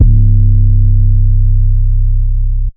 archived music/fl studio/drumkits/slayerx drumkit/808s